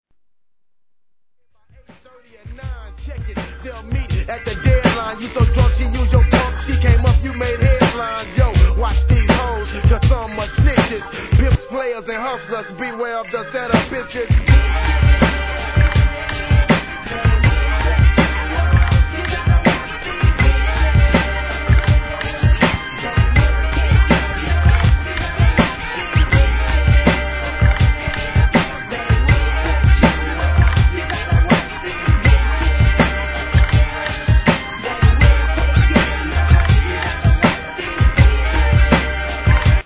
1. G-RAP/WEST COAST/SOUTH